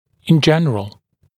[ɪn ‘ʤenrəl][ин ‘джэнрэл]в общем